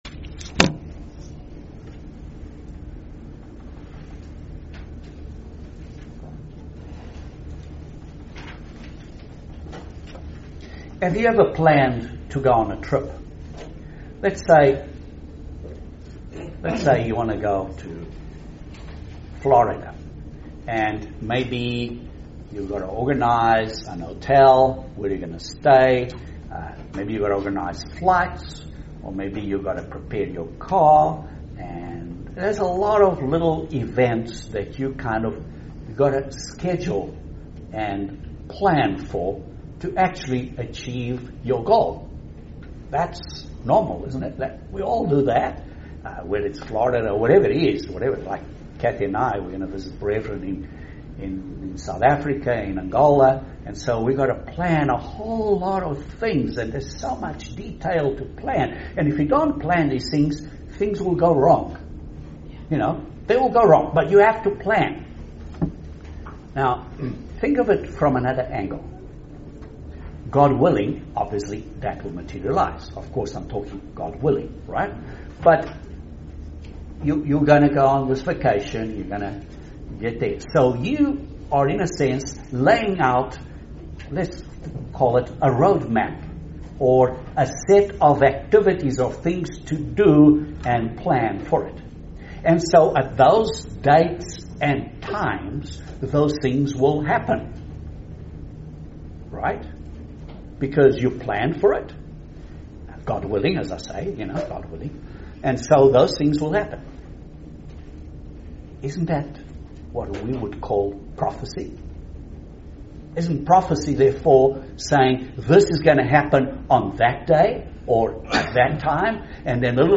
Is there a Modern day Israel? Join us for this amazing sermon.